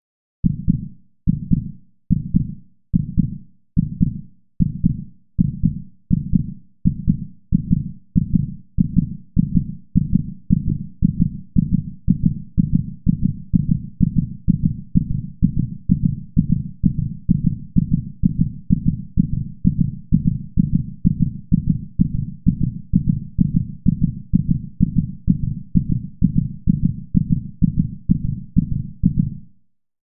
دانلود آهنگ تپش قلب تند که شدیدتر می شود از افکت صوتی انسان و موجودات زنده
دانلود صدای تپش قلب تند که شدیدتر می شود از ساعد نیوز با لینک مستقیم و کیفیت بالا
جلوه های صوتی